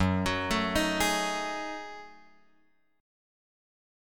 F# Minor 6th Add 9th